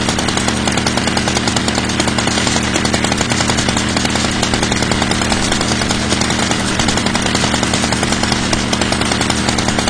small_engine_22KHz.wav